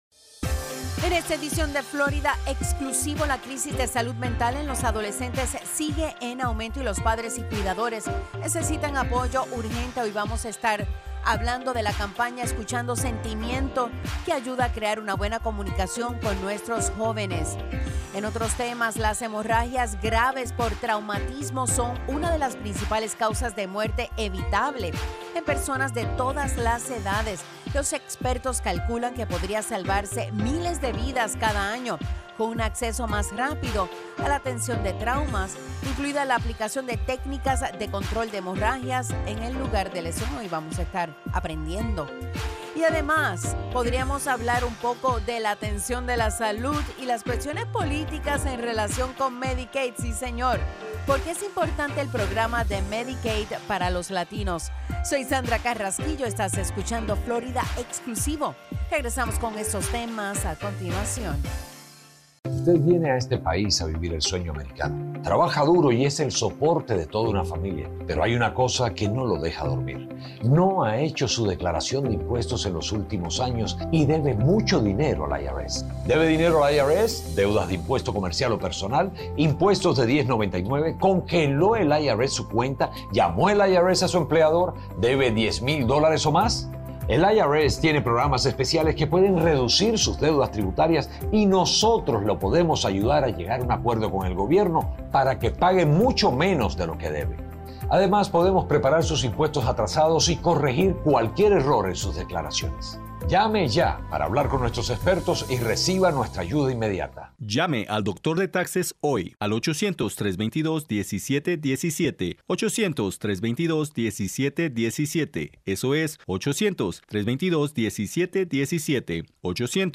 FNN's Florida Exclusivo is a weekly, one-hour news and public affairs program that focuses on news and issues of the Latino community.